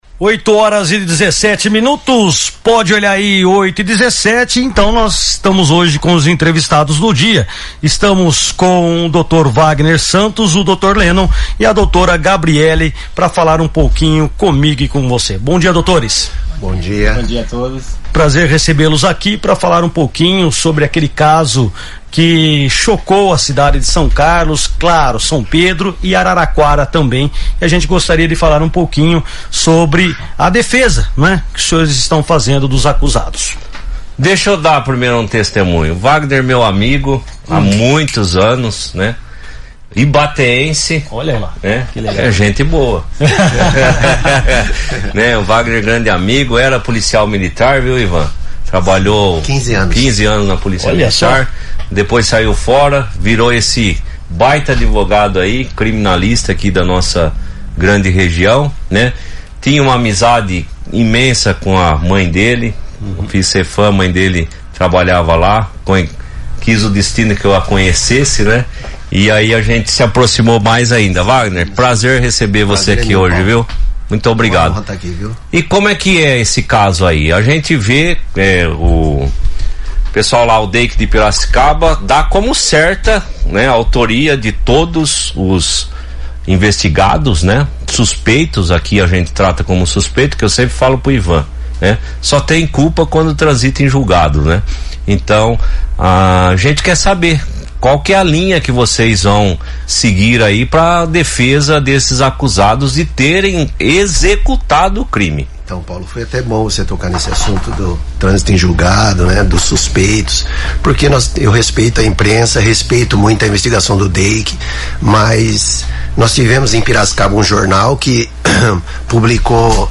advogados-1.mp3